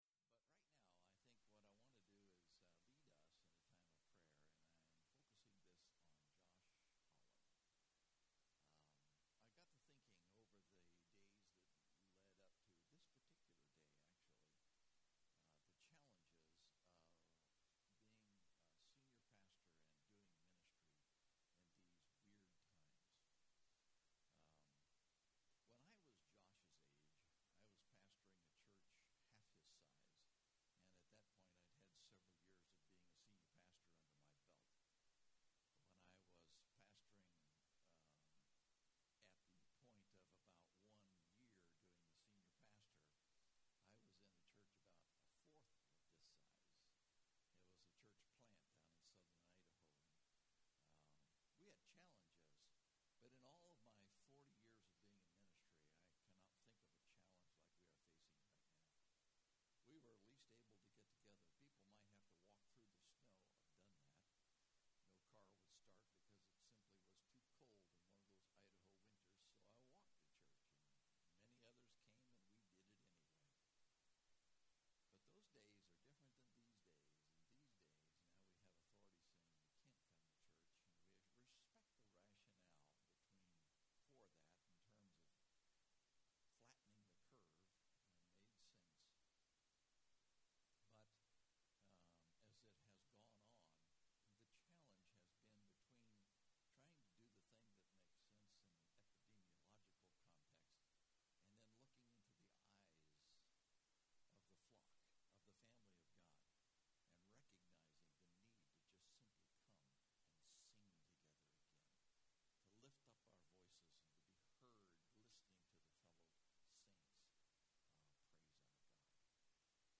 Online Church Service